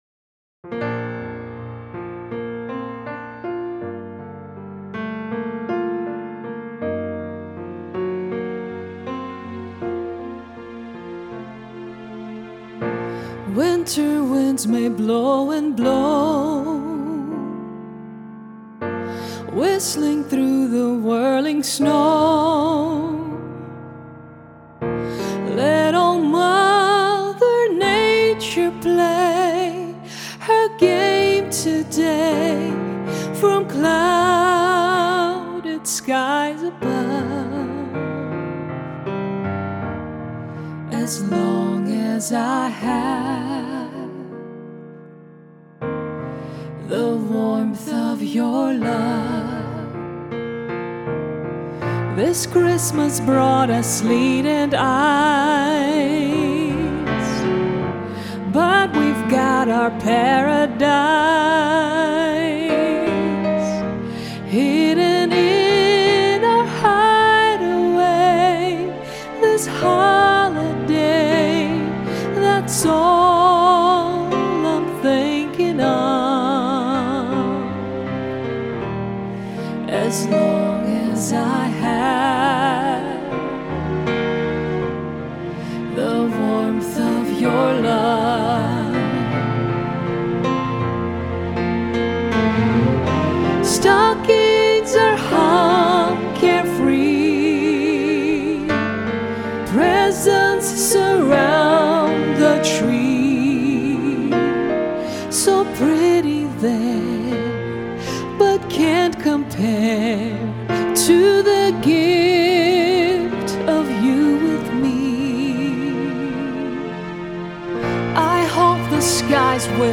"The Warmth Of Your Love (At Christmas Time)" (holiday song)